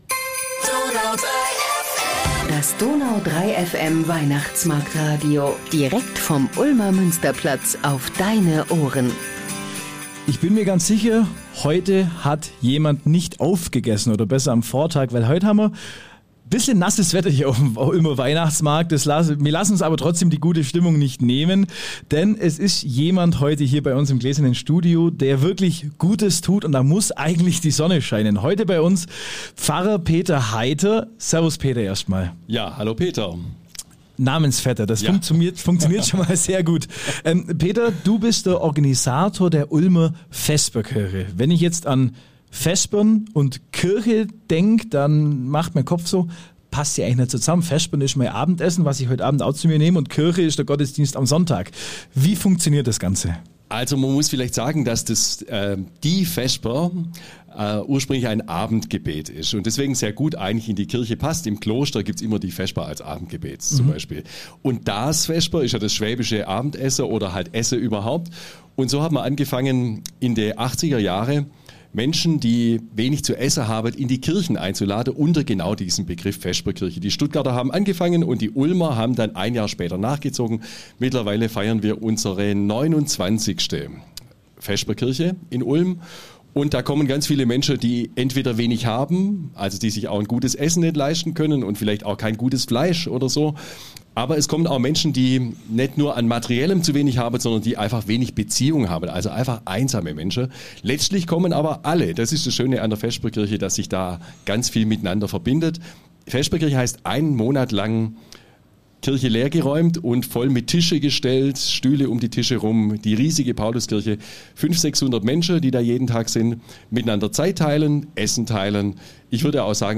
zu Gast im Weihnachtsmarktradio.